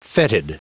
Prononciation du mot fetid en anglais (fichier audio)
Prononciation du mot : fetid